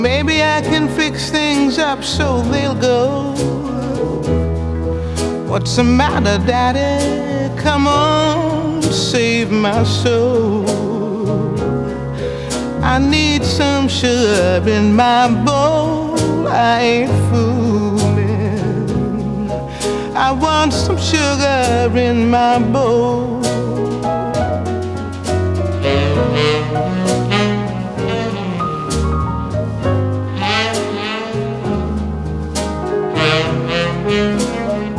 # Blues